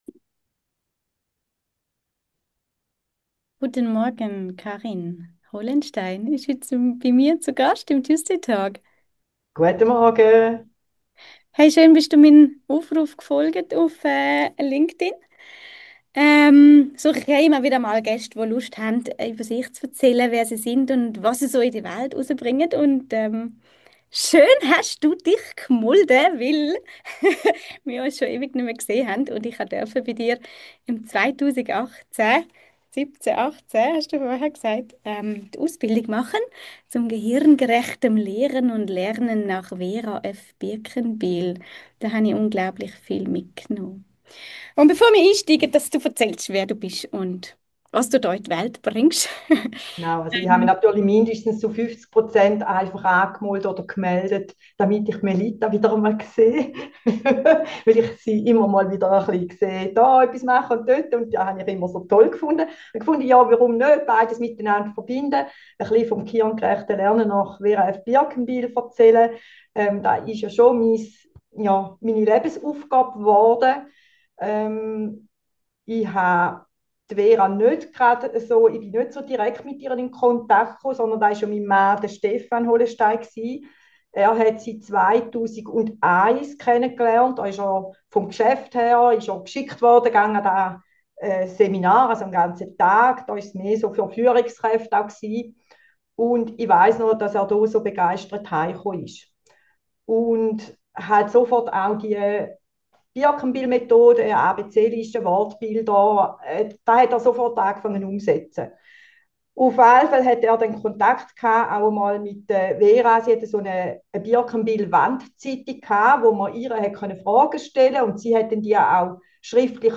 Hier ist alles ECHT - kein Filter, kein Schnitt, kein irgendwas. So wie wir miteinander gesprochen haben, so hörst du hier rein - als ob du live dabei wärst.
Es kann passieren, dass mal die Technik ihren Eigenlauf nimmt.